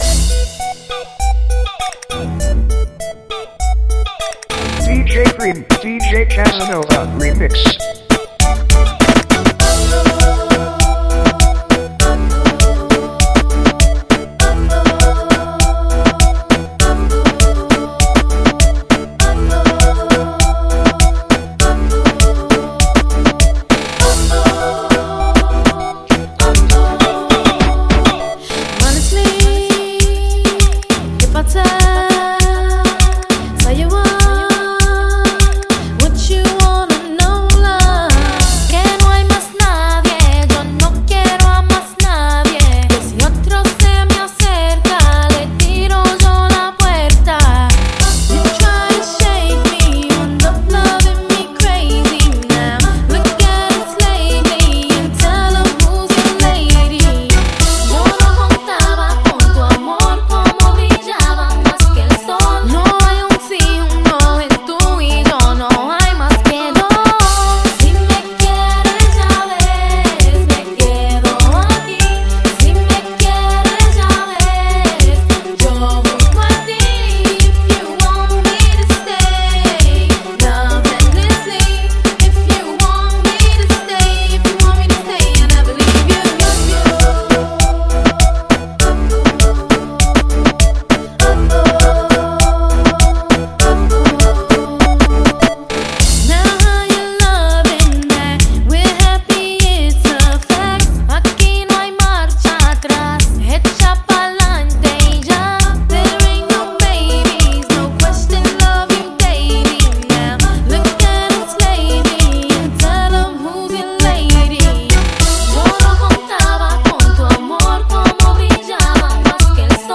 miondoko ya reggaeton
reggaeton remix